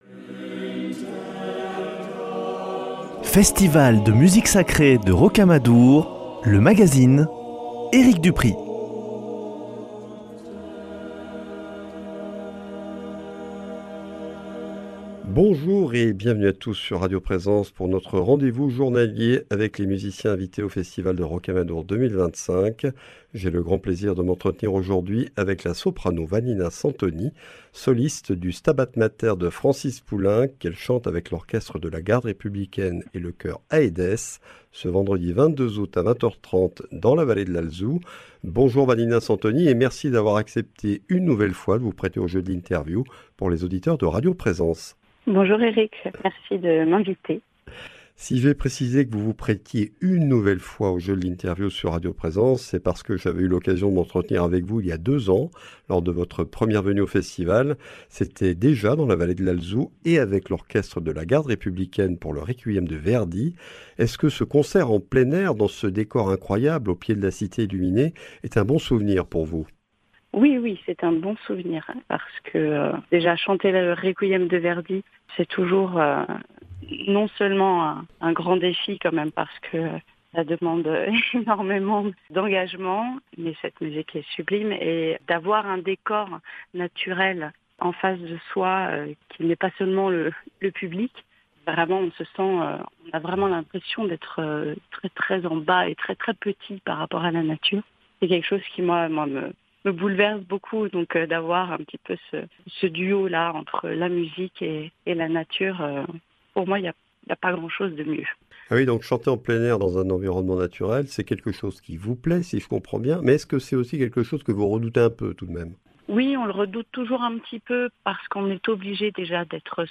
Festival de Rocamadour 2025 : ITW de Vannina Santoni